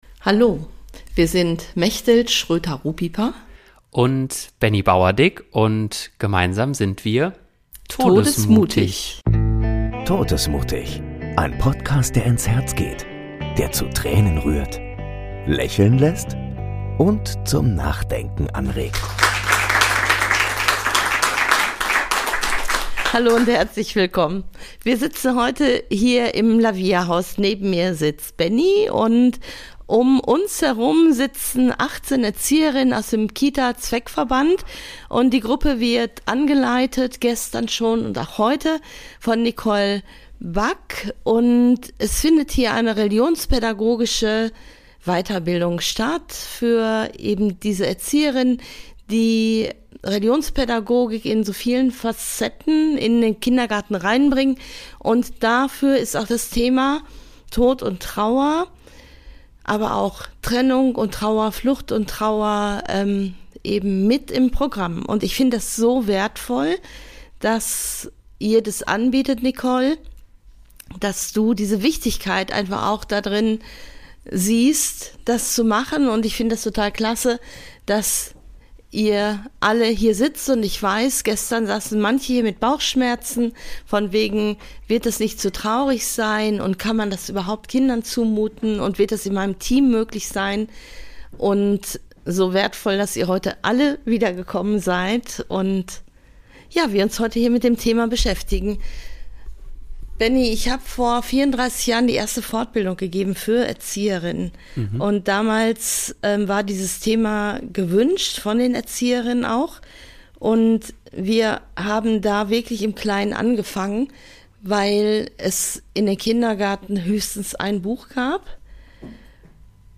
Darum geht es in dieser Live-Podcast Folge